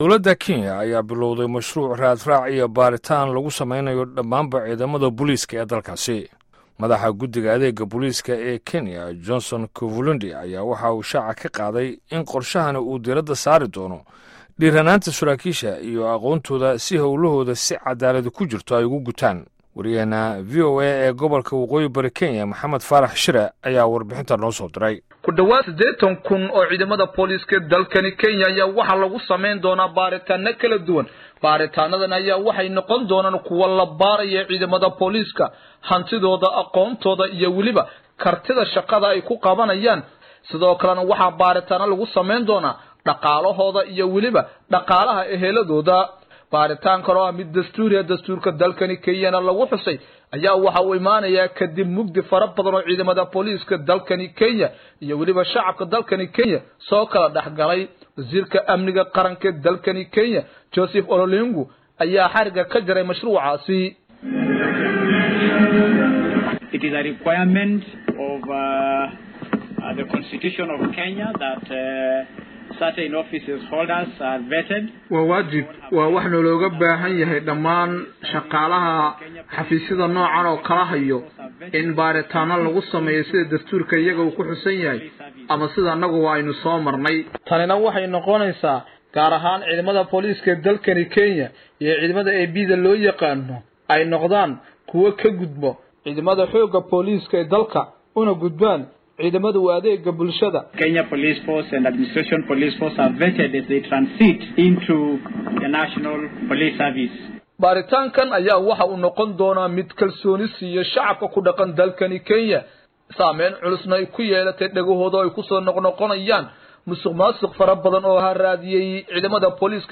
Warbixinta Booliiska Kenya - 4:24